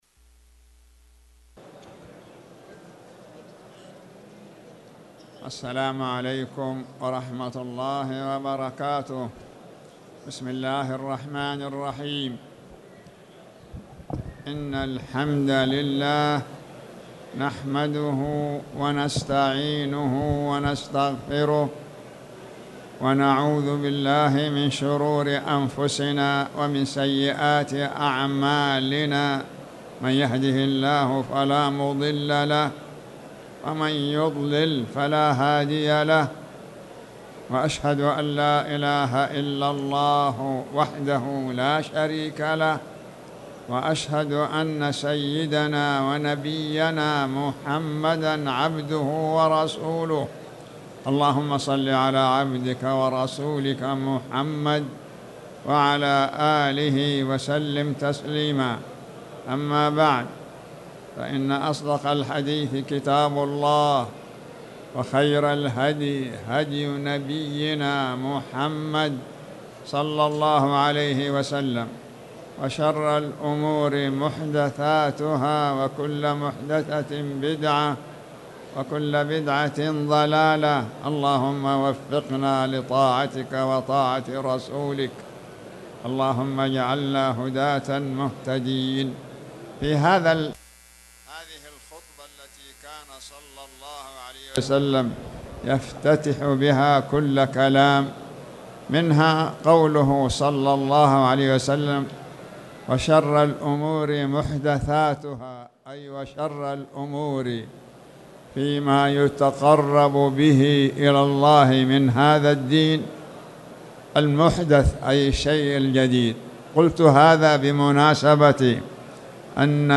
تاريخ النشر ١٤ ربيع الثاني ١٤٣٩ هـ المكان: المسجد الحرام الشيخ